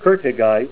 Name Pronunciation: Kottigite + Pronunciation Synonym: Koettigite Kottigite Image Images: Kottigite Comments: Köttigite crystals on gossanous matrix up to 0.5 cm in length.